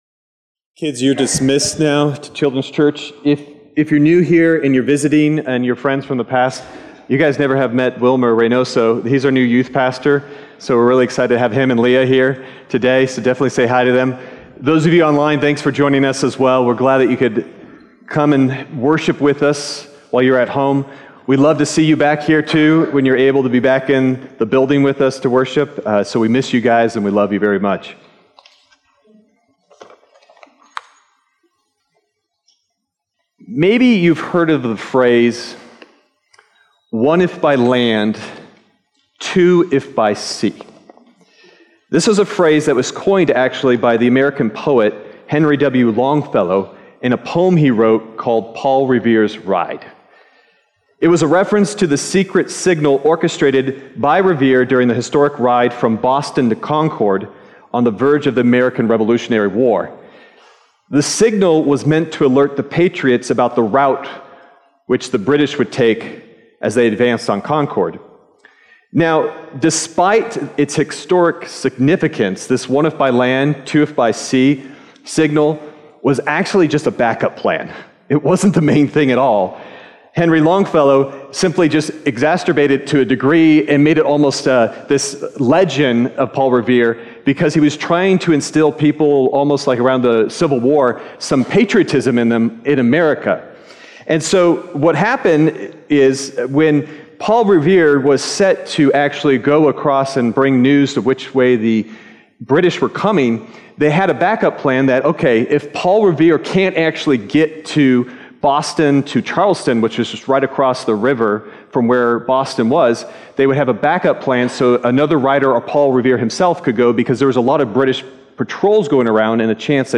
Sermon Detail
February_4th_Sermon_Audio.mp3